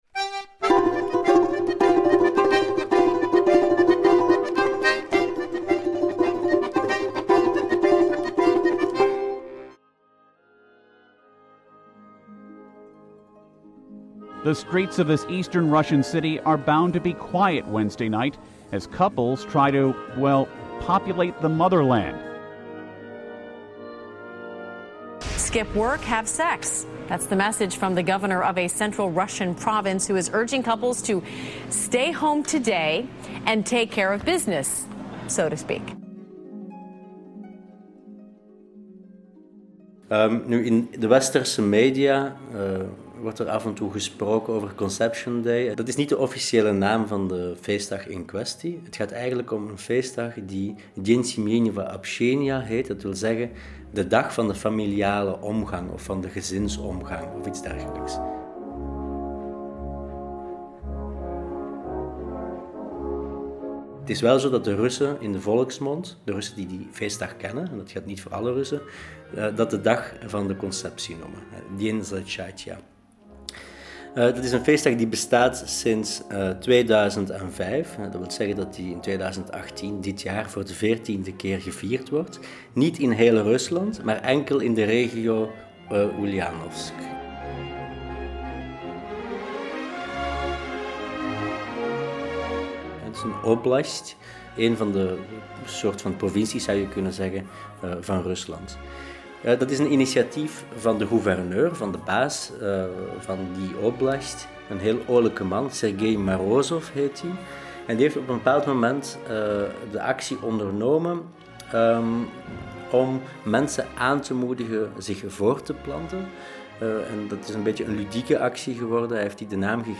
Reportage van 21Bis over “Conceptiedag” in Oeljanovsk